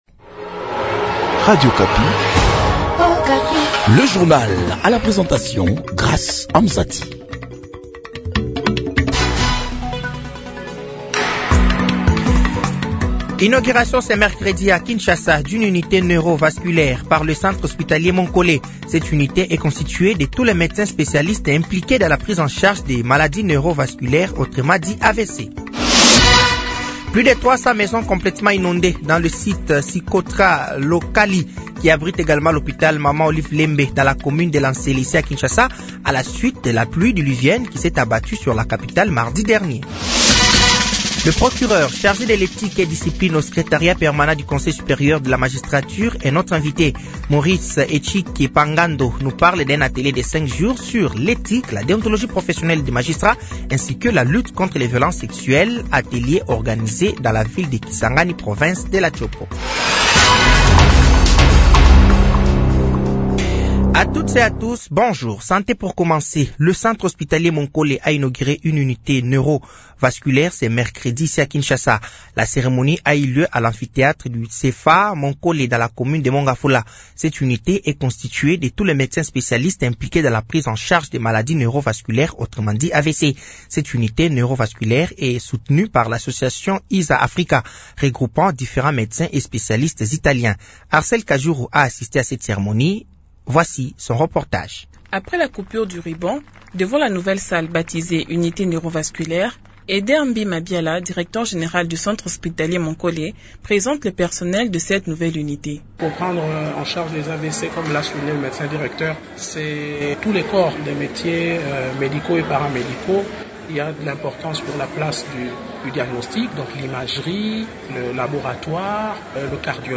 Journal français de 08h de ce jeudi 07 novembre 2024